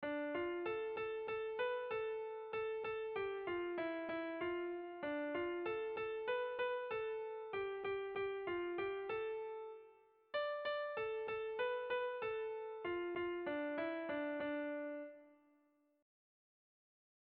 Erlijiozkoa
Inongo konpasik gabeko doinu interesgarria derizkiot.
A1A2B